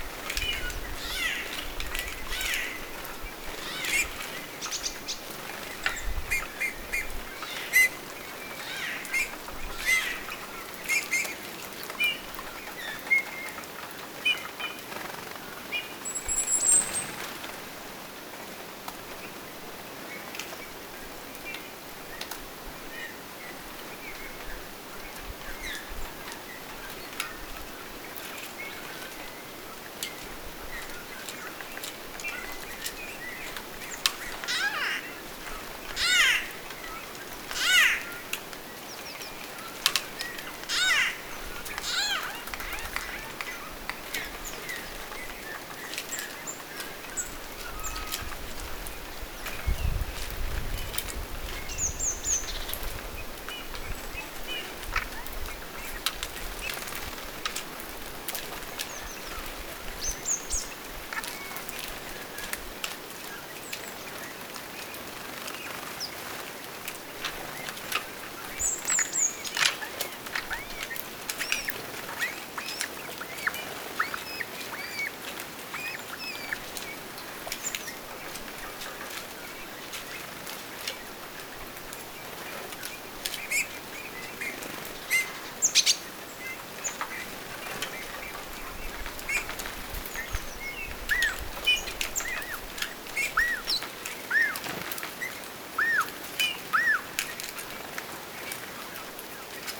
näyte pähkinähakin keväisestä ääntelystä
nayte_pahkinahakin_ilmeisesti_laulusta.mp3